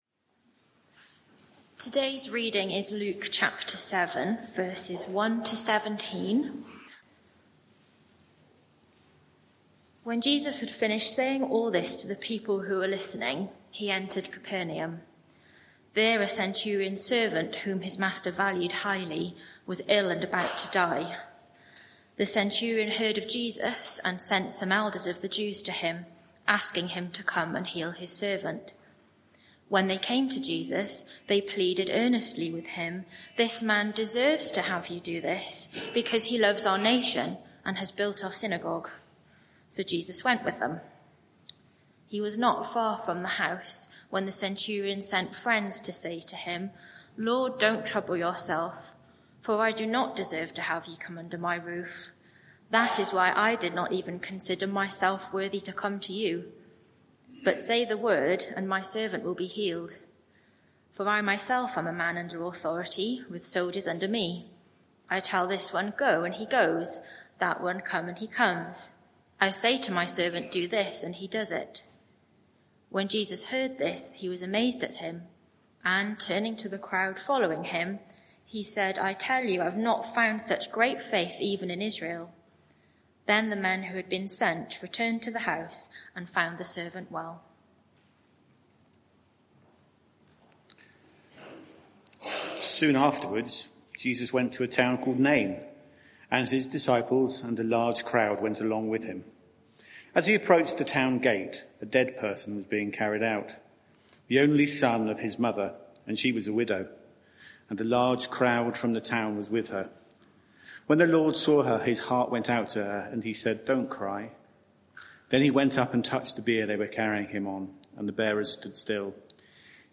Media for 11am Service on Sun 09th Mar 2025 11:00 Speaker
Series: What a Saviour! Theme: Luke 7:1-17 Sermon (audio) Search the media library There are recordings here going back several years.